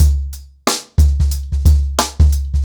TrackBack-90BPM.49.wav